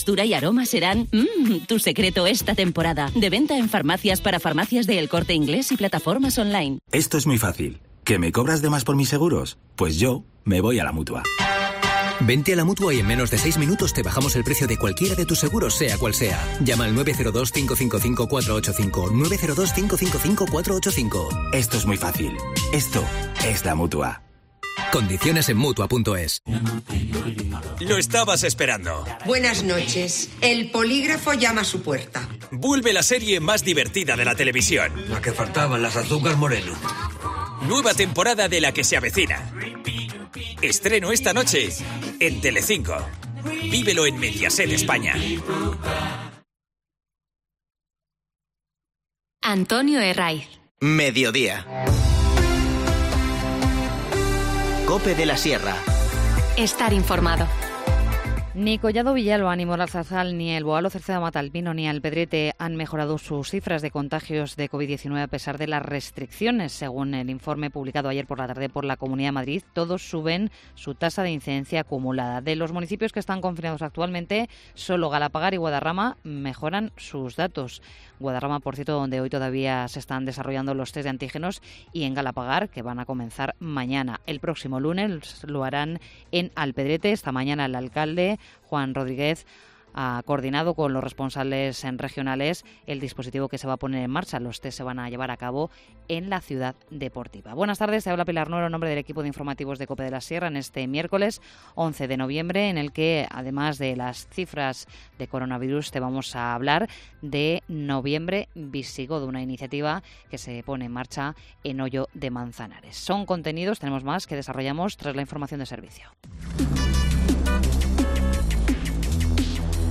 Informativo Mediodía 11 noviembre